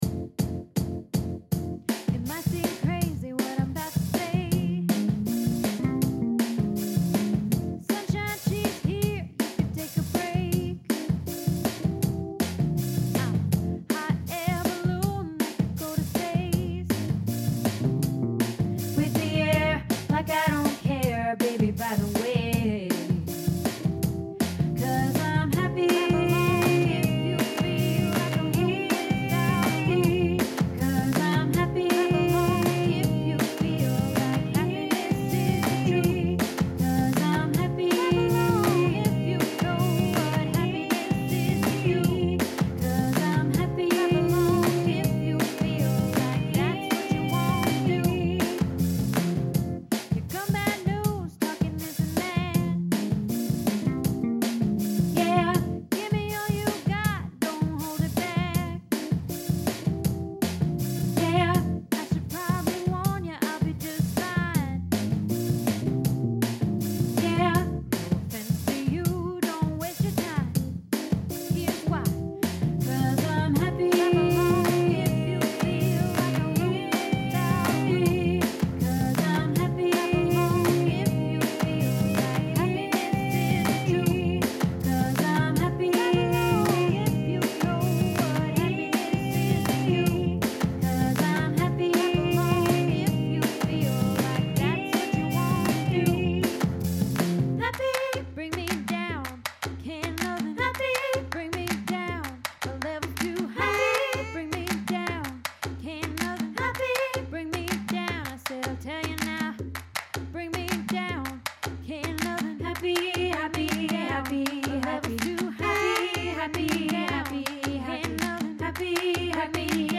Happy Tenor